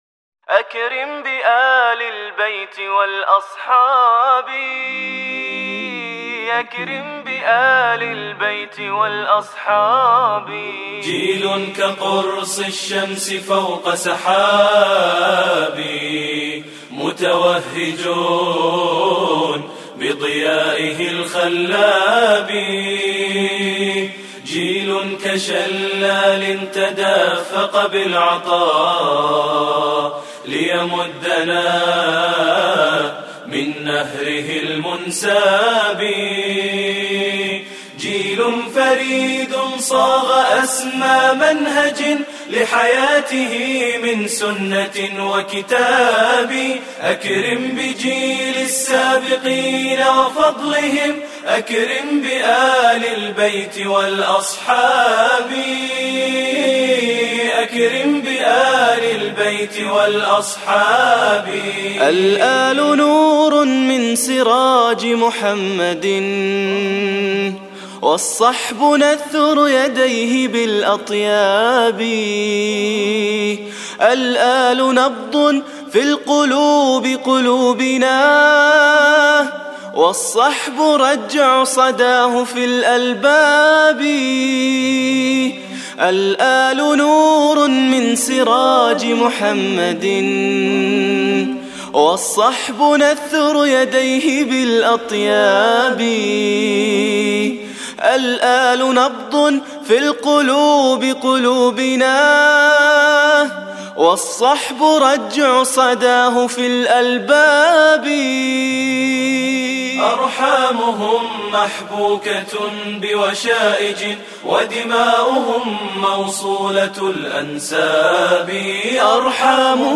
الاناشيد